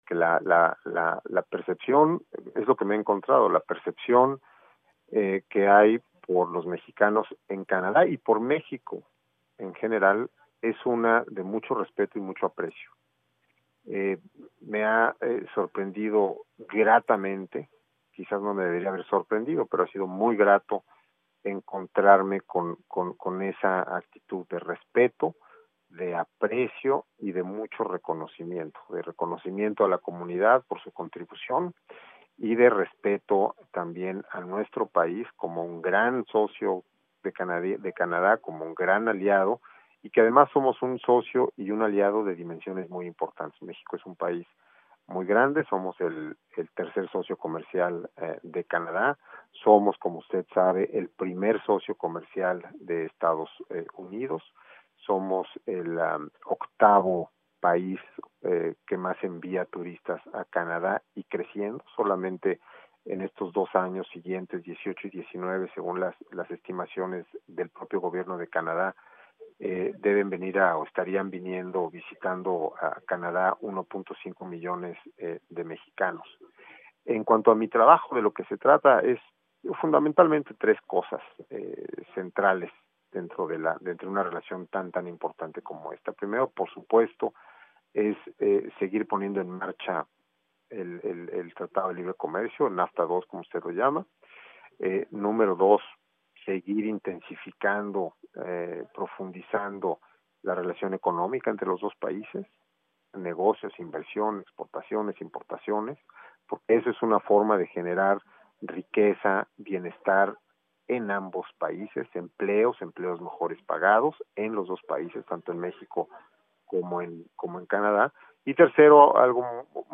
Cárteles, comercio e inmigración: entrevista con nuevo embajador mexicano en Canadá
(Nota: La entrevista fue realizada el viernes 7 de junio del 2019)